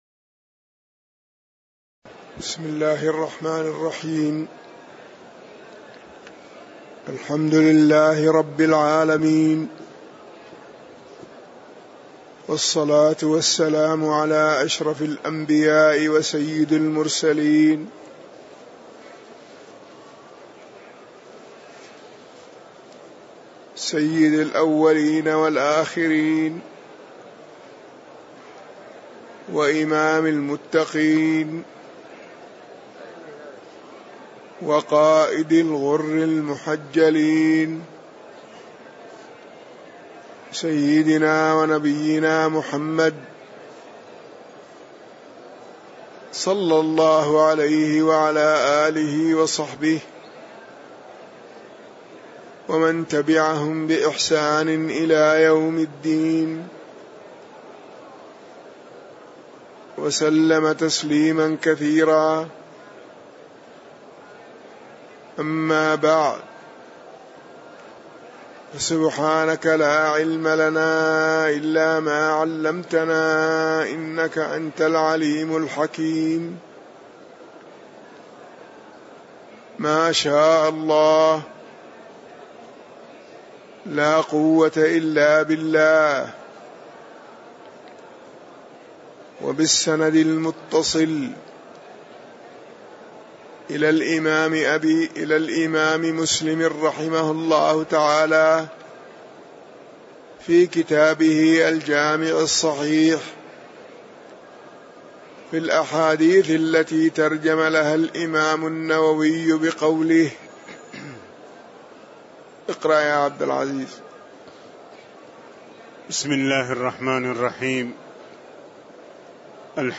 تاريخ النشر ٢ رمضان ١٤٣٧ هـ المكان: المسجد النبوي الشيخ